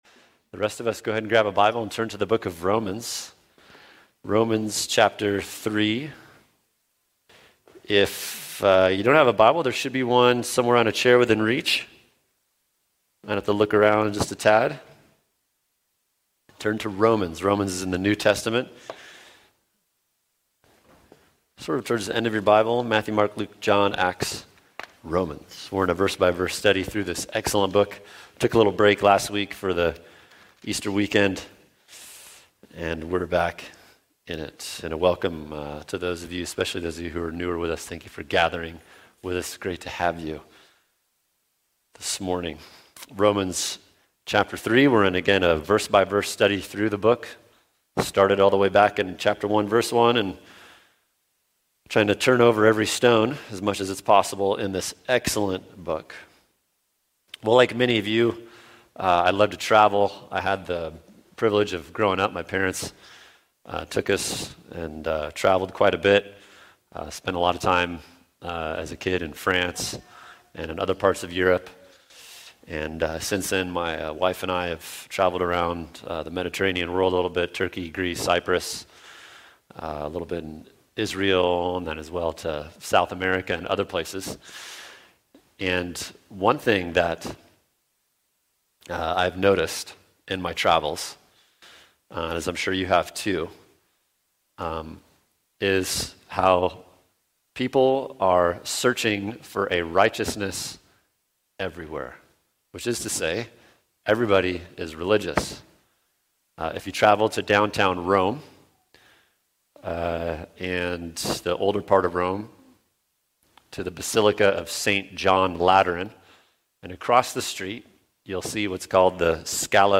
[sermon] Romans 3:21 The Kind of Righteousness We Need | Cornerstone Church - Jackson Hole